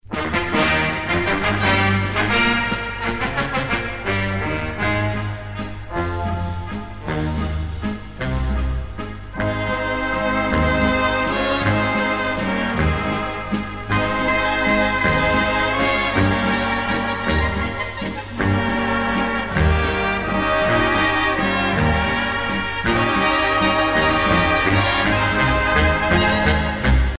danse : valse